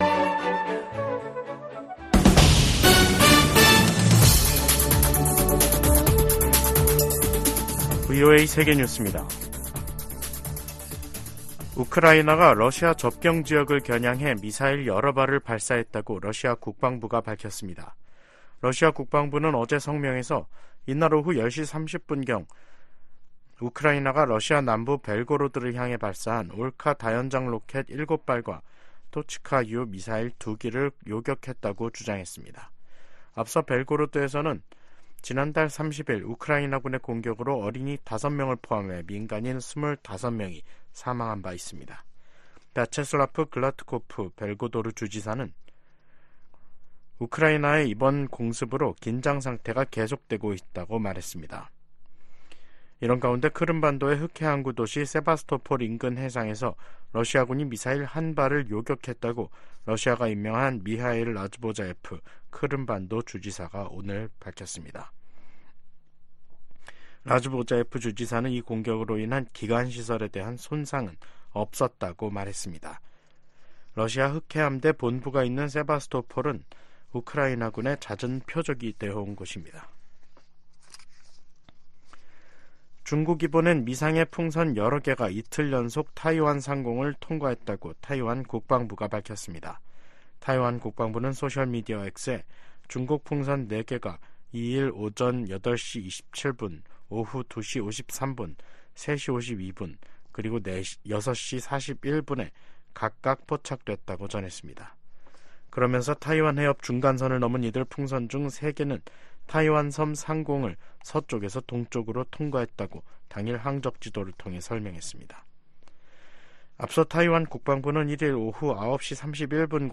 VOA 한국어 간판 뉴스 프로그램 '뉴스 투데이', 2024년 1월 3일 2부 방송입니다.